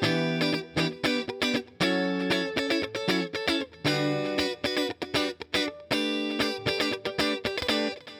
11 Rhythm Guitar PT4.wav